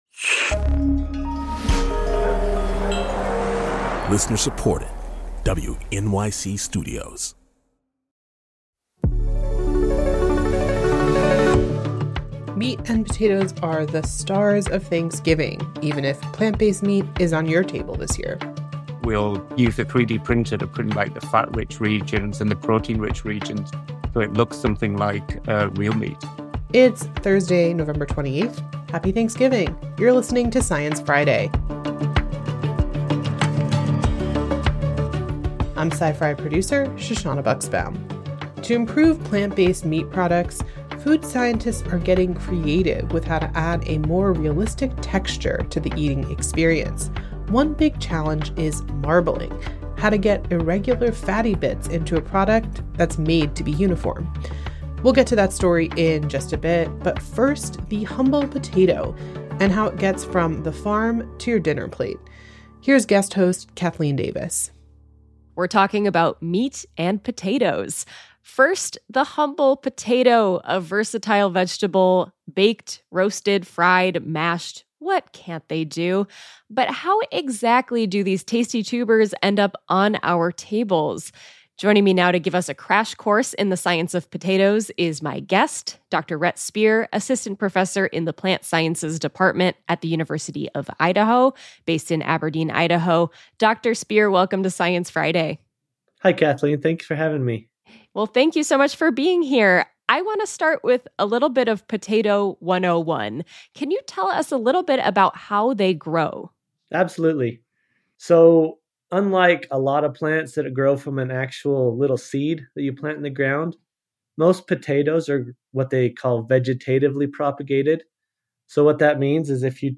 Just in time for Thanksgiving, a potato researcher explains potato varieties, potato nutrition, and some tubular tuber facts. And, irregular, fatty marbling gives meat a unique texture.